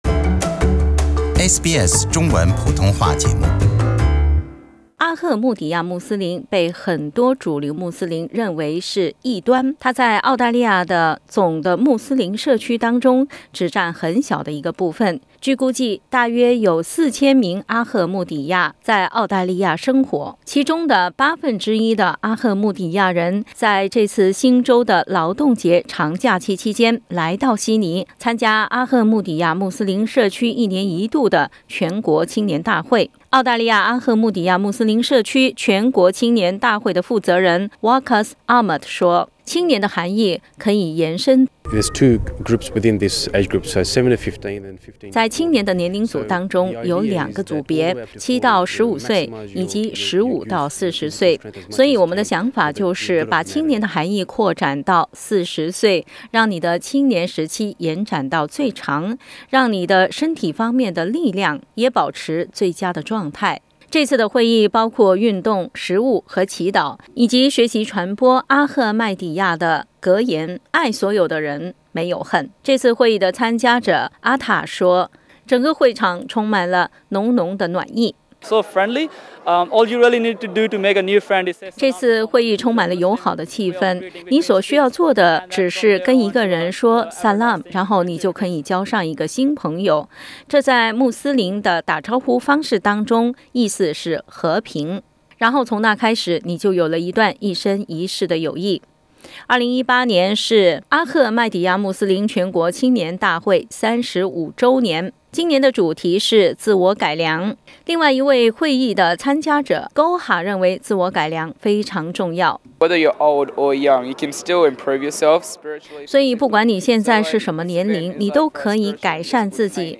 Source: SBS SBS 普通话电台 View Podcast Series Follow and Subscribe Apple Podcasts YouTube Spotify Download (5.02MB) Download the SBS Audio app Available on iOS and Android 到底多少岁的人可以把自己成为青年？ 在刚刚过去的新州劳动节，一个叫做阿赫迈底亚的穆斯林教派在悉尼召开其社区的第35届全国青年大会。